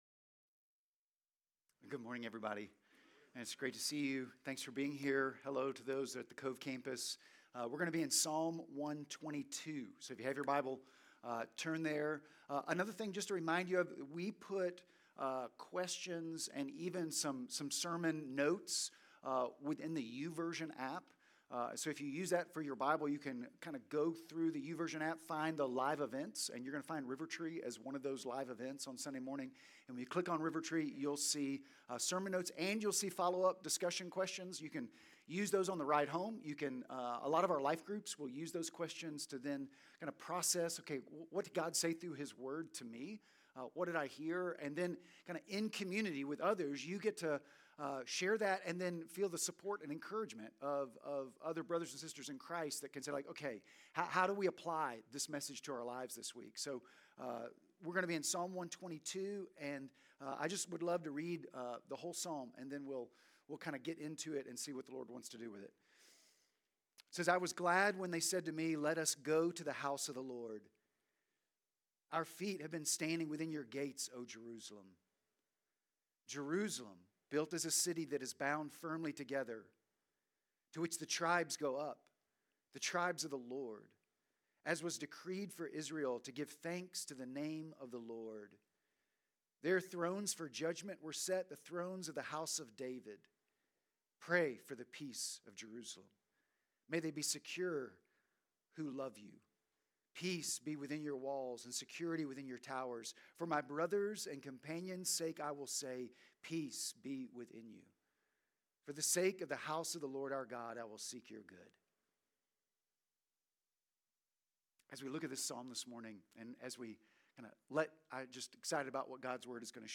Sermon Notes Sermon Audio…